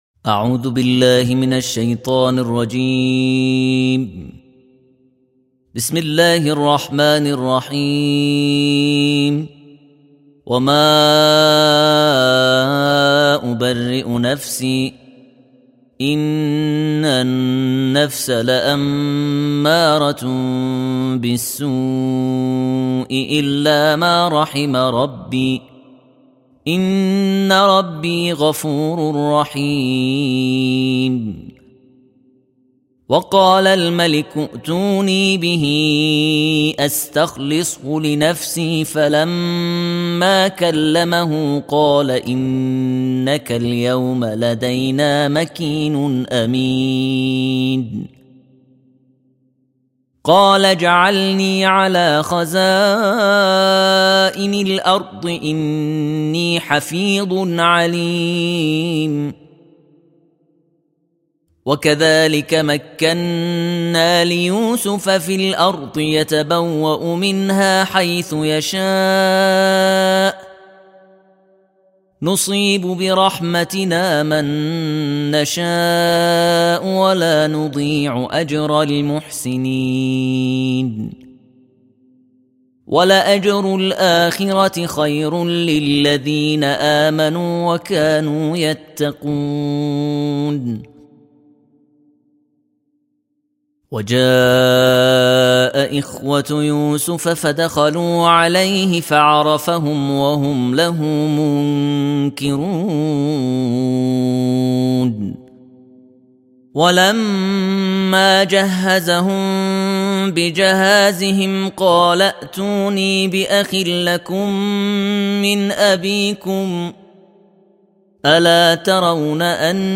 دانلود ترتیل جزء سیزدهم قرآن